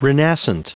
Prononciation du mot renascent en anglais (fichier audio)
Prononciation du mot : renascent